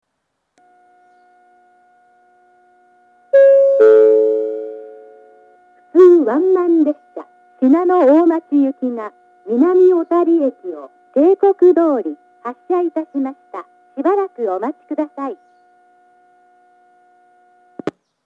１番線上り南小谷駅発車案内放送 普通ワンマン信濃大町行の放送です。